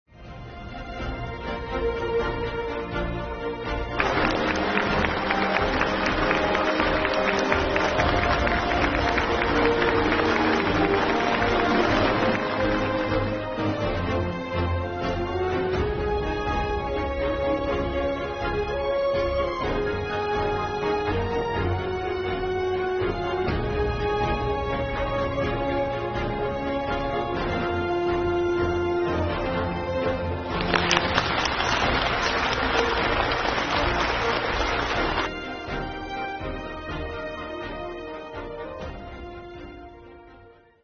浦东开发开放30周年庆祝大会12日上午在上海世博中心举行。中共中央总书记、国家主席、中央军委主席习近平出席大会并发表重要讲话。